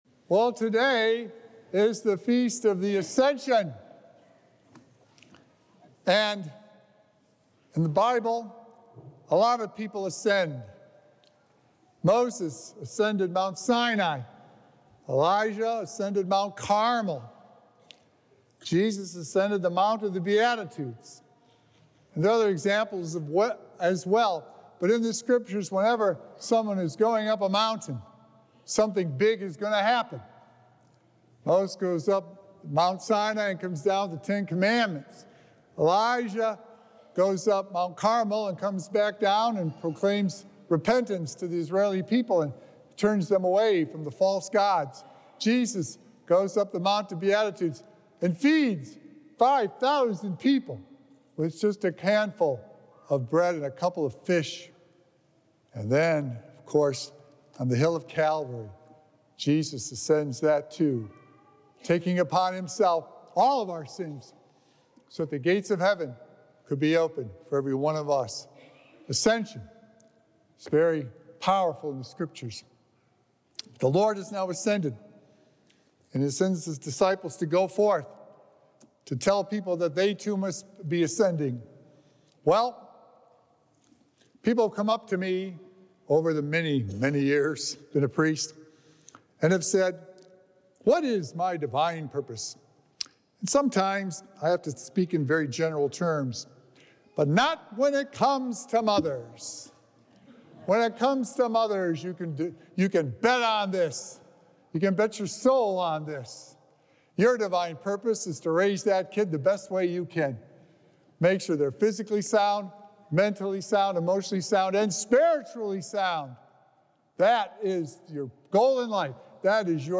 Recorded Live at St. Malachy Catholic Church on Sunday, May 12th, 2024.